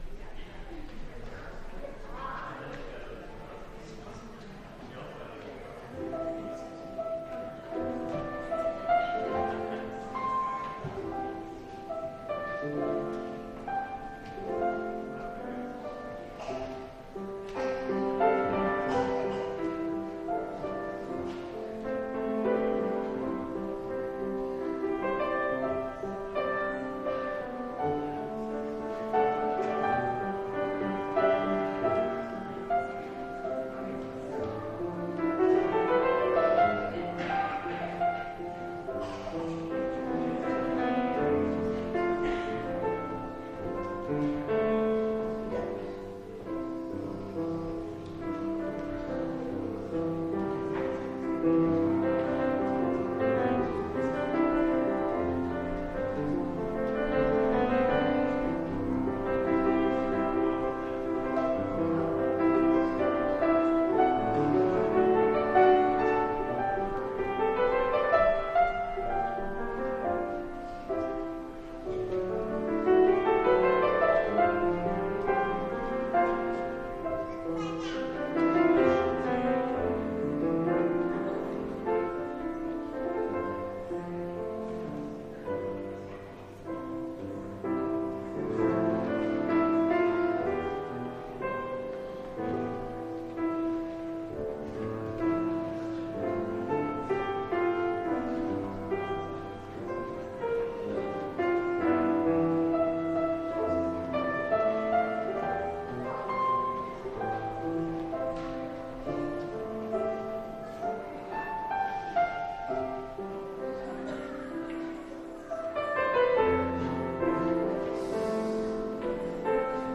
Entire December 24th Service